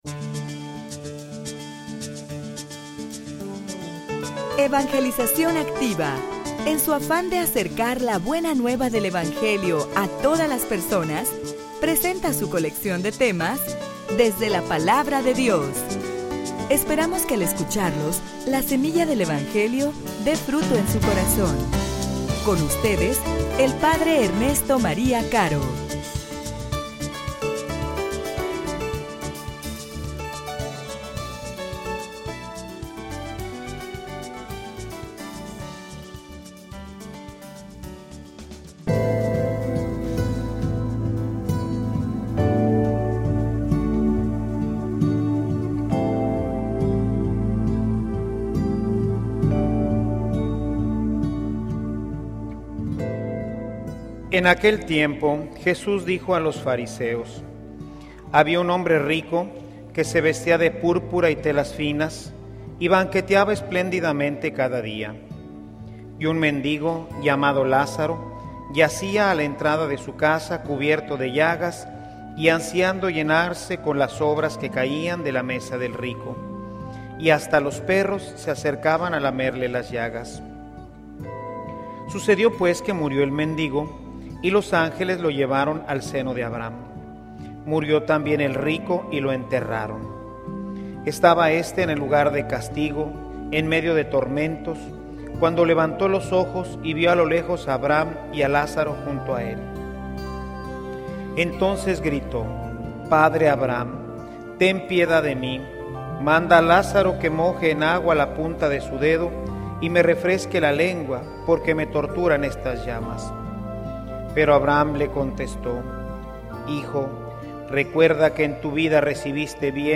homilia_Lo_que_es_imposible_para_el_hombre_es_posible_para_Dios.mp3